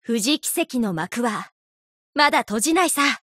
贡献 ） 协议：Copyright，作者： Cygames ，其他分类： 分类:富士奇石语音 您不可以覆盖此文件。